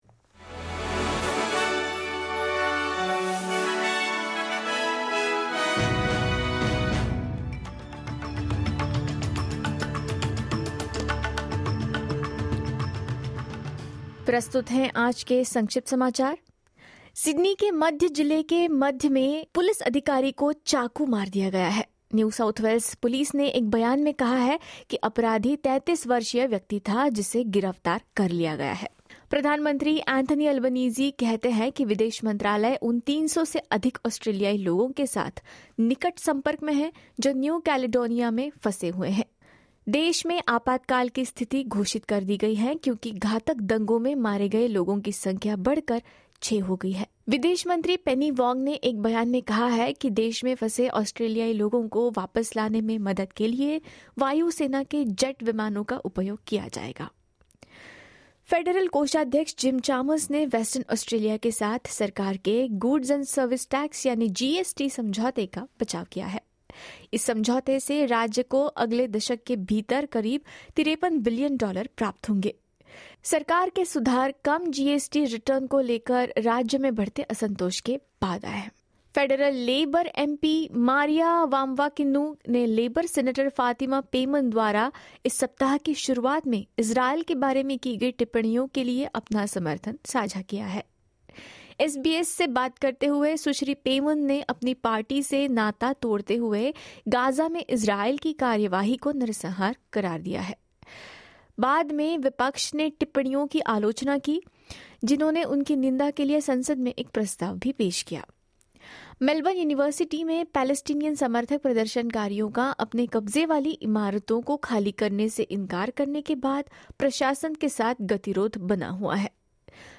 SBS Hindi Newsflash 19 May 2024: New South Wales police officer stabbed in central Sydney